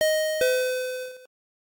Elevator end.ogg